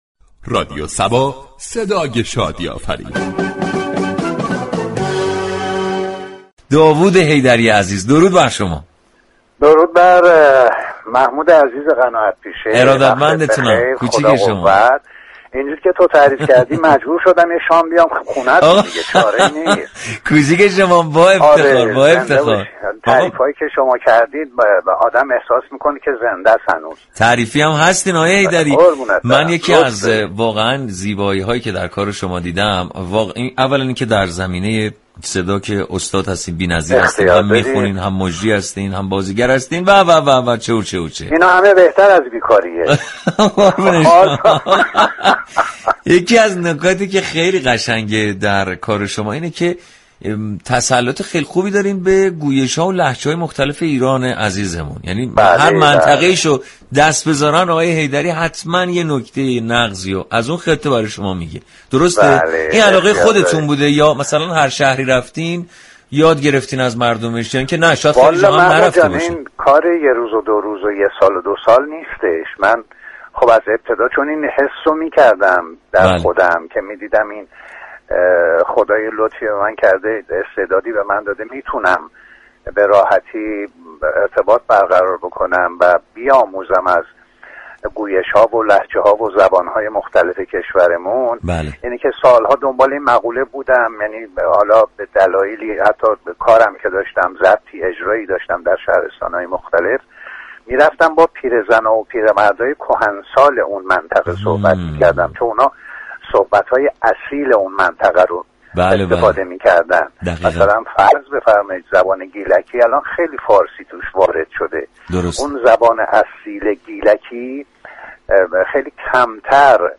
مهمان تلفنی برنامه صباهنگ شد.